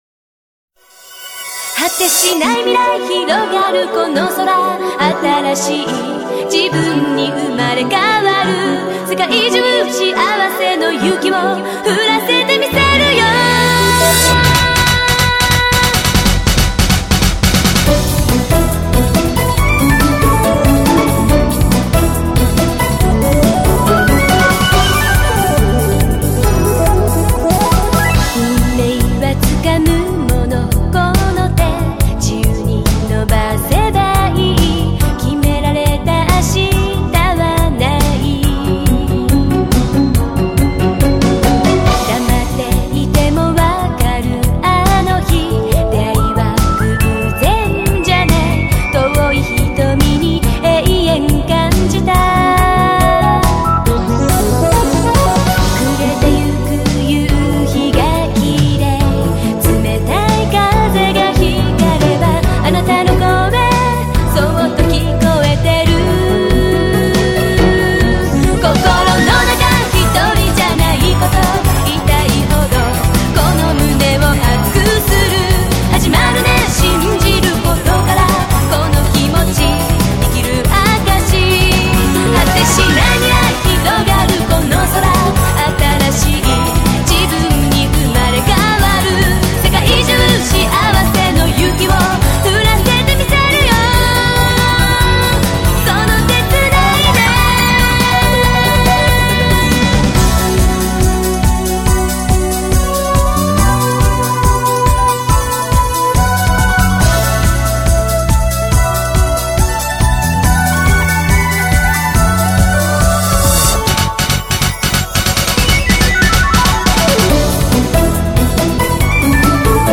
Audio QualityCut From Video